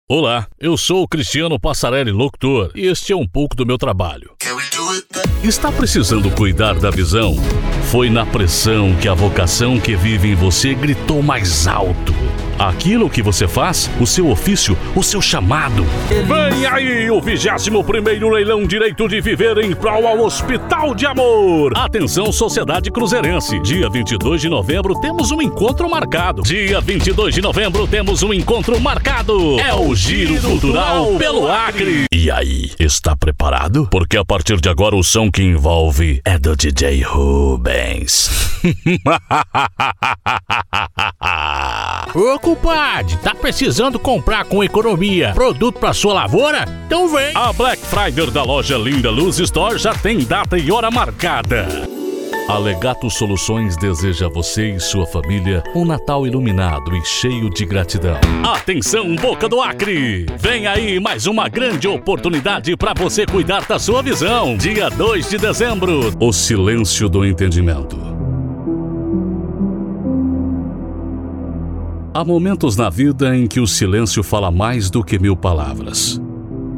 Spot Comercial
Padrão
Impacto
Animada
Caricata